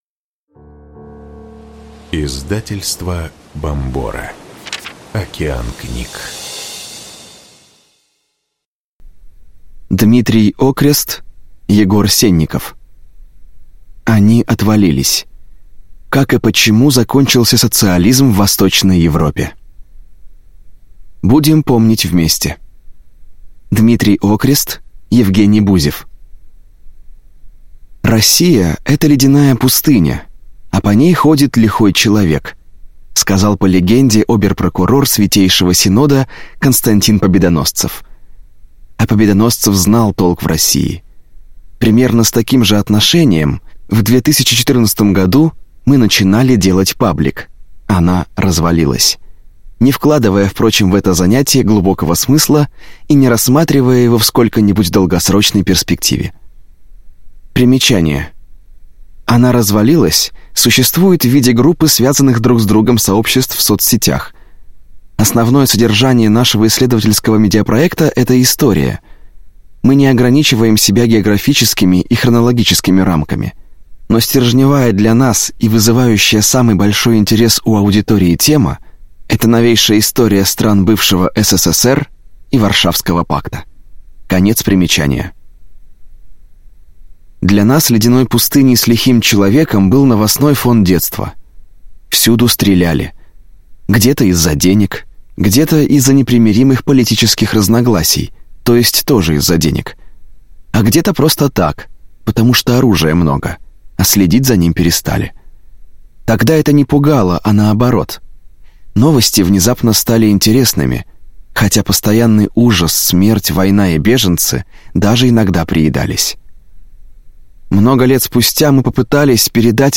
Аудиокнига Они отвалились: как и почему закончился социализм в Восточной Европе | Библиотека аудиокниг